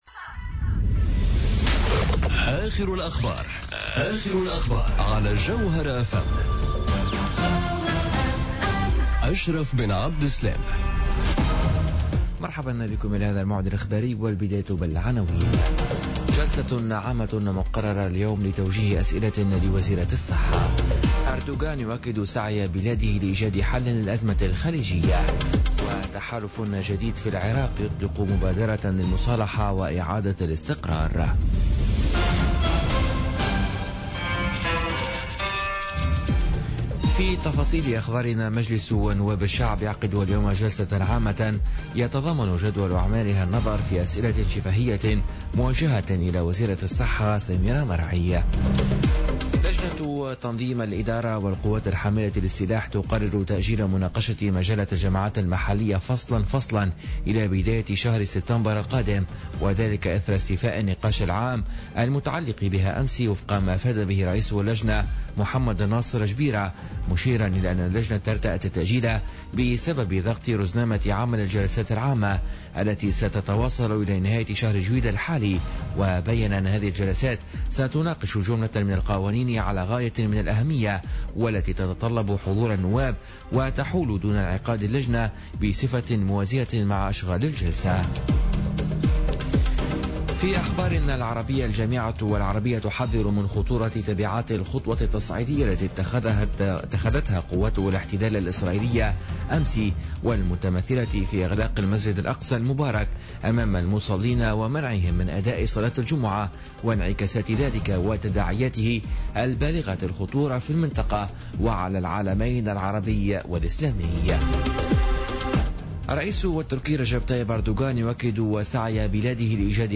نشرة أخبار منتصف الليل ليوم السبت 15 جويلية 2017